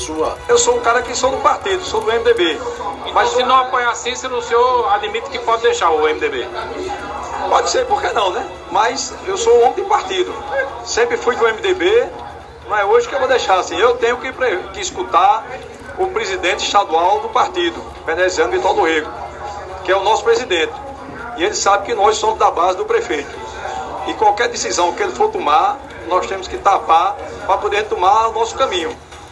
Durante entrevista ao programa Arapuan Verdade, da Rádio Arapuan FM, desta terça-feira (07/11), Mikika declarou que segue na base de Cícero e que vai dialogar com Veneziano, que é presidente estadual do MDB, para ver os rumos do partido e tomar sua própria decisão a depender dos caminhos adotados pelo senador.